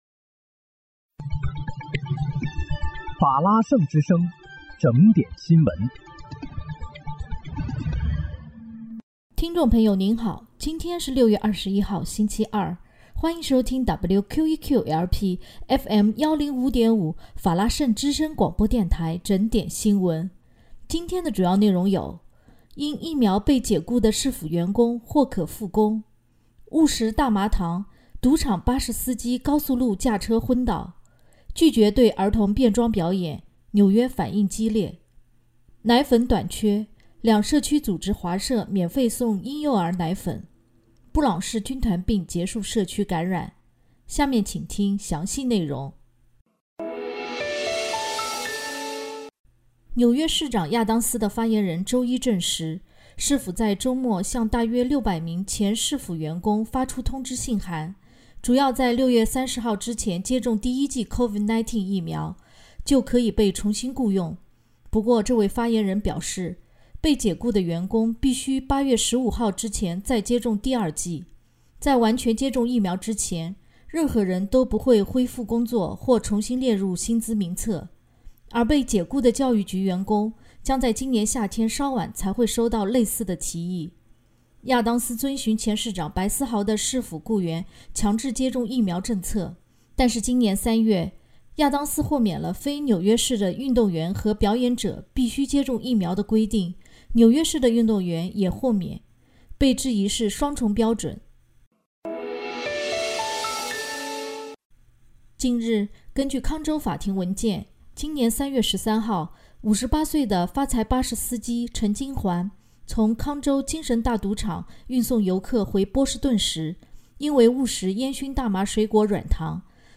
6月21日（星期二）纽约整点新闻